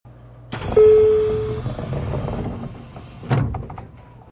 開閉時、どちらも多少暗めの一点チャイムが鳴る。　813系や303系と大きな相違点は無いが、閉まり方は静かである。